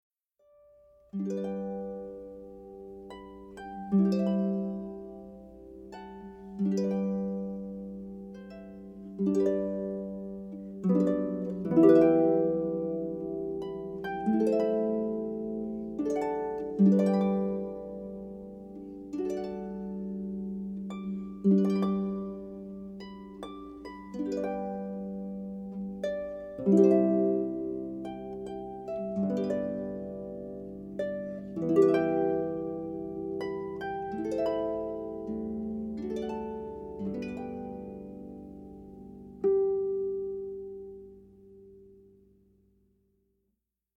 harp and piano
in Winchester, Virginia
Religious